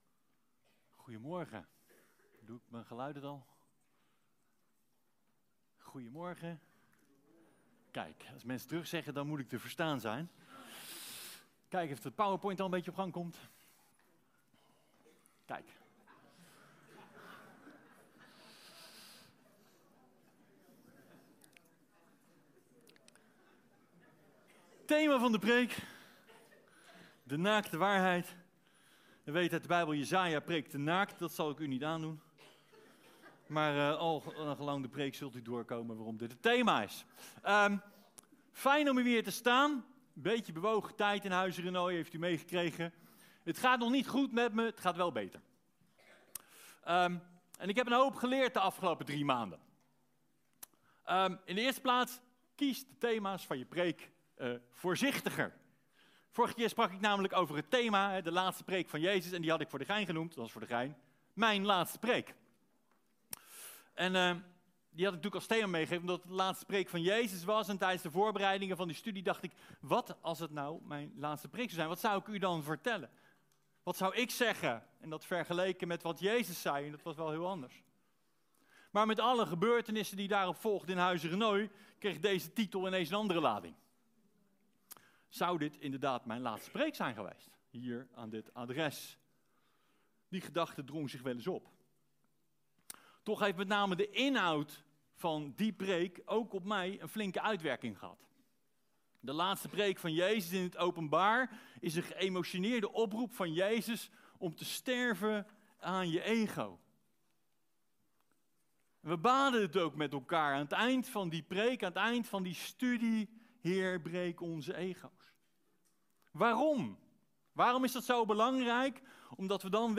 In deze preek, De naakte waarheid, word je meegenomen naar het hart van het Evangelie: sterven aan jezelf om werkelijk te leven. Aan de hand van Johannes 13 ontdek je hoe Jezus, de Koning der koningen, Zichzelf vernederde en als een slaaf de voeten van Zijn discipelen waste.